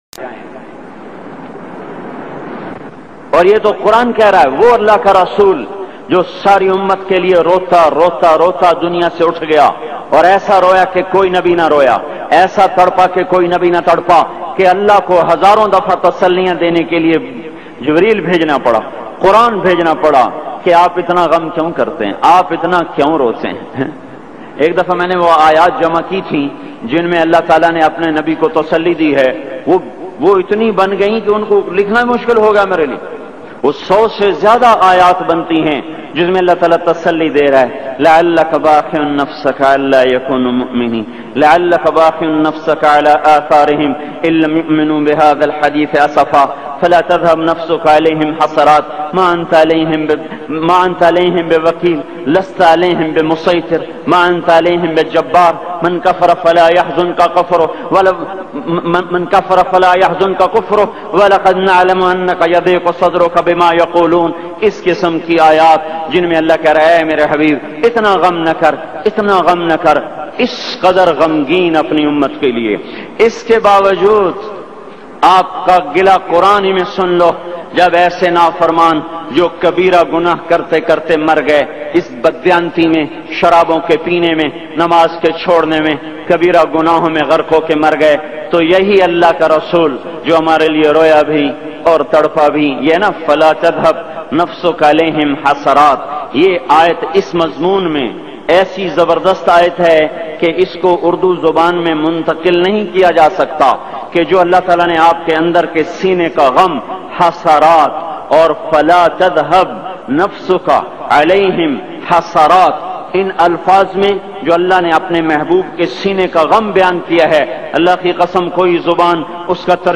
Most Emotional & Cryfull Bayan Maulana Tariq Jameel
Ansoo-Barah-Bayan.mp3